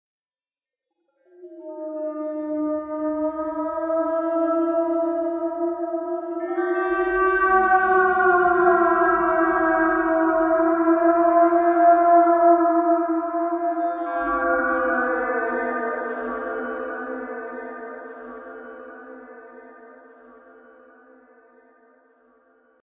Scary High Pitched Ghost Efecto de Sonido Descargar
Scary High Pitched Ghost Botón de Sonido